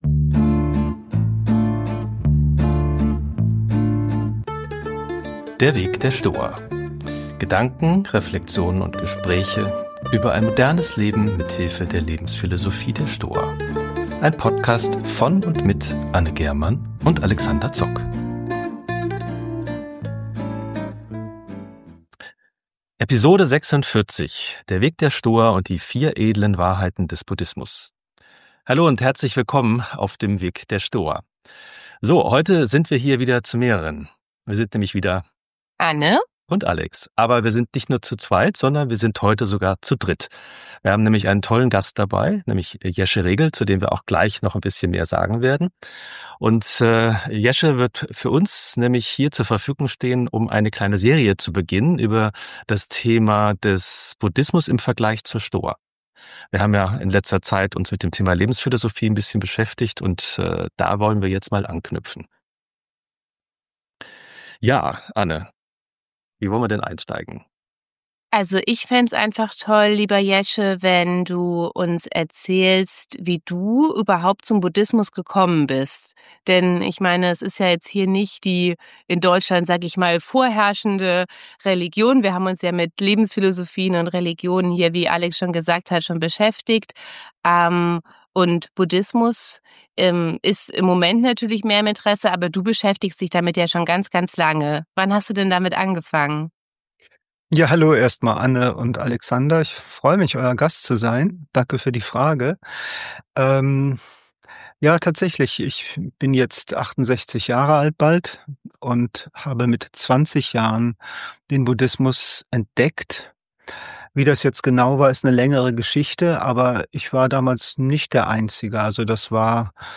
Das Gespräch wird in den nächsten Episoden fortgesetzt.